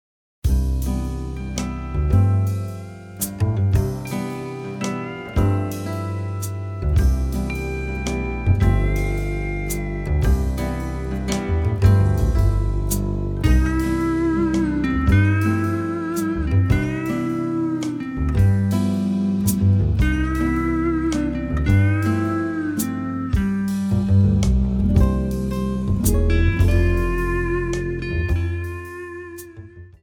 seven-string acoustic guitar